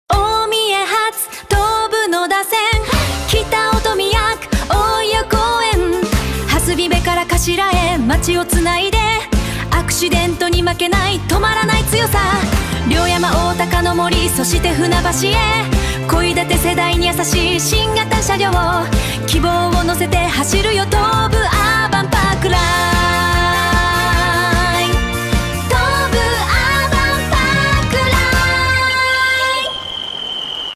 テキストで指示を出すだけで、歌詞の書き下ろしからメロディ、歌声の生成までをわずか数十秒で行なってくれます。
スタイル 8-bit
ただ、日本語としての発音に違和感があるところや、何と言っているのか聞き取れないところもありました。